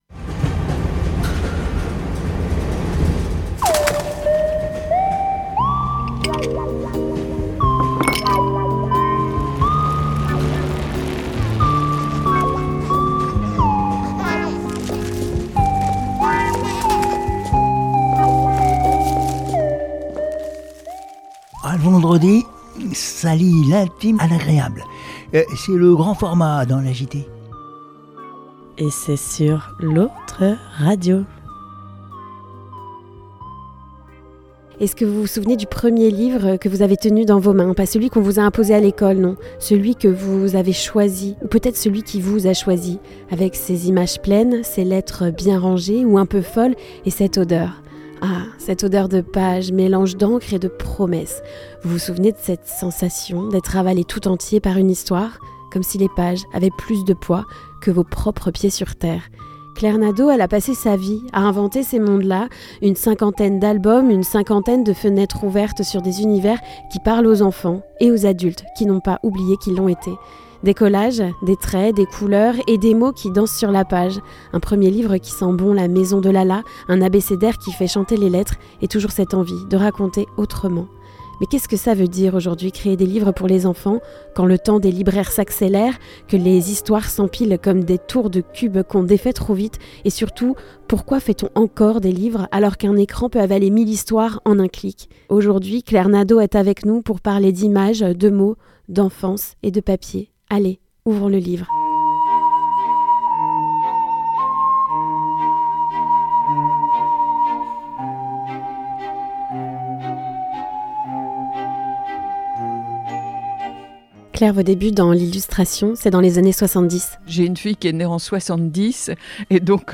Une rencontre sensible et passionnante.